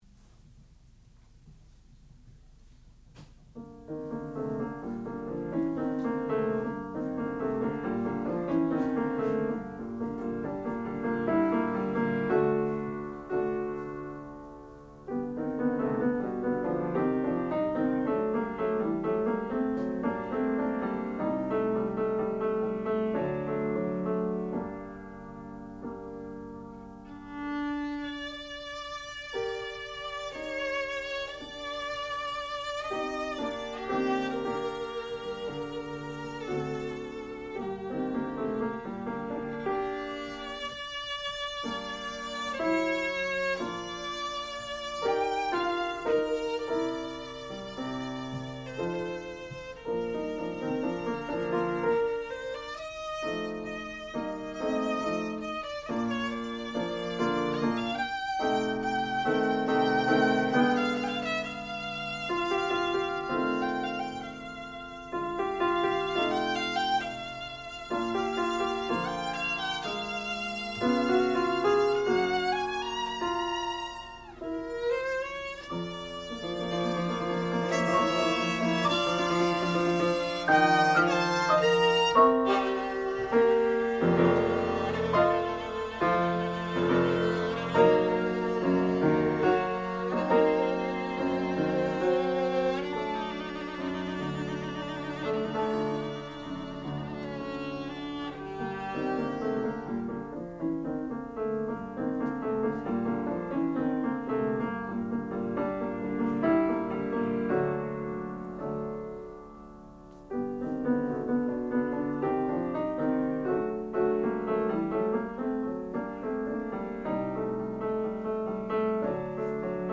sound recording-musical
classical music
violin
piano
Advanced Recital